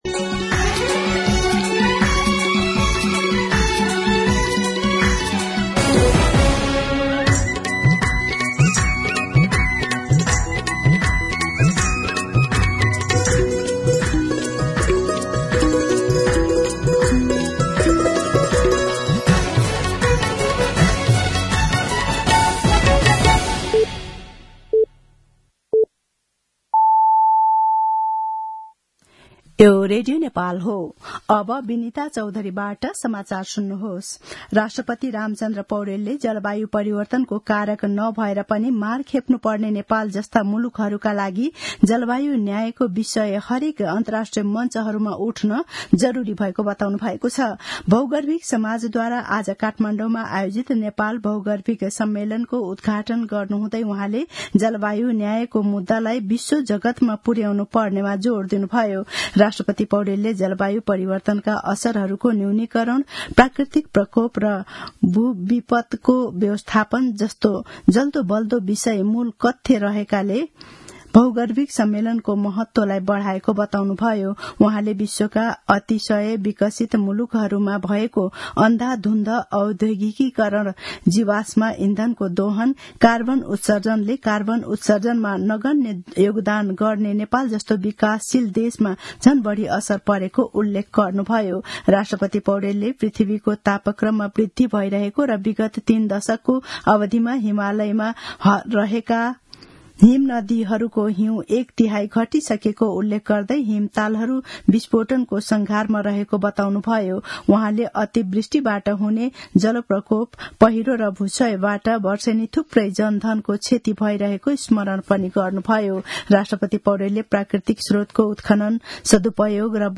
मध्यान्ह १२ बजेको नेपाली समाचार : २७ फागुन , २०८१